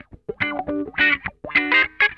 CRUNCHWAH 13.wav